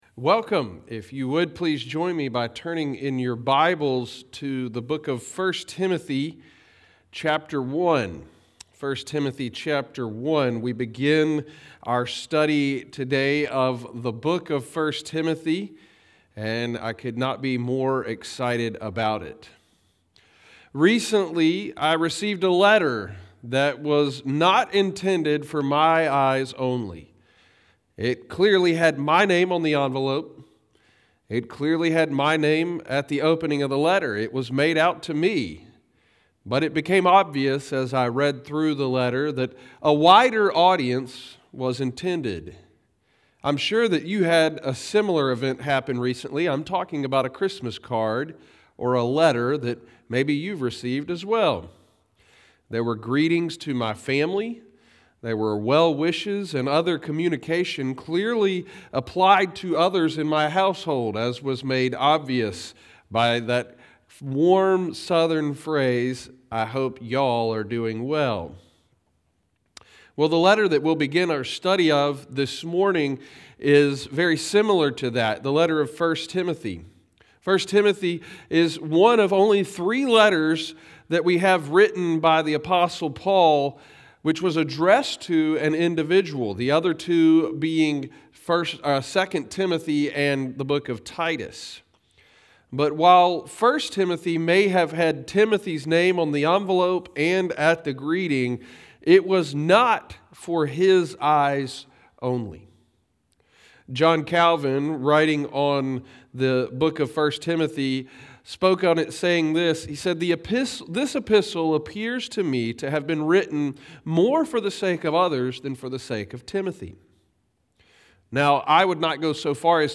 *Note: the audio for this sermon was rerecorded on Monday, January 9th.